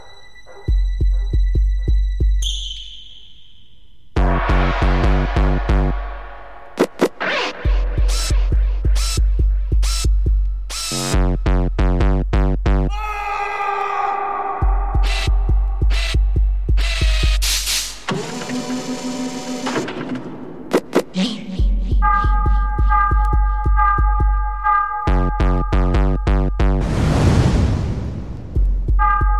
TOP >Vinyl >Grime/Dub-Step/HipHop/Juke
instrumental